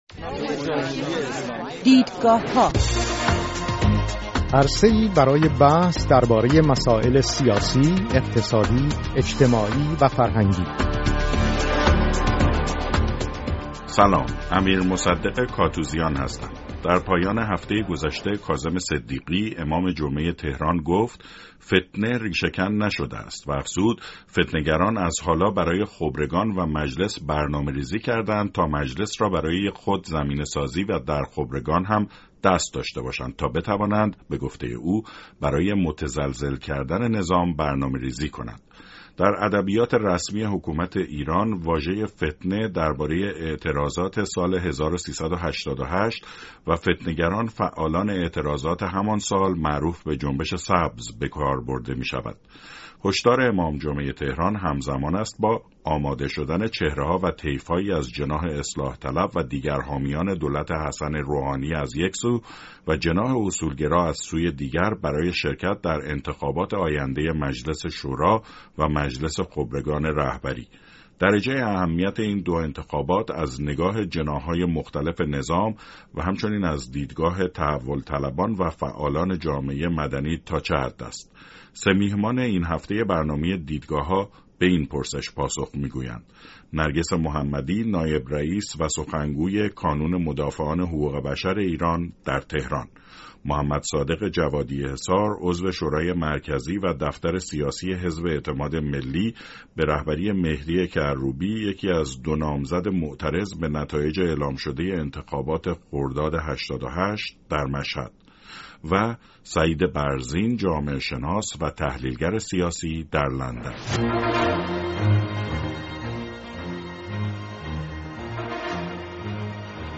درجه اهمیت این دو انتخابات از نگاه جناح‌های مختلف نظام و همچنین از دیدگاه تحول‌طلبان و فعالان جامعه مدنی تا چه حد است؟‌ سه میهمان این هفته برنامه دیدگاه‌ها به این پرسش پاسخ می‌گویند.